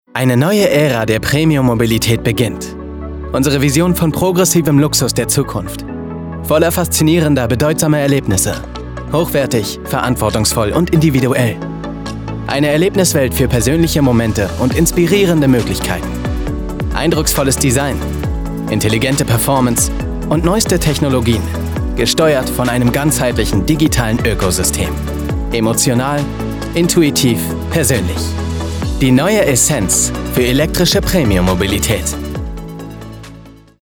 sehr variabel, hell, fein, zart, markant
Jung (18-30)
Werbung Supermarkt
Commercial (Werbung)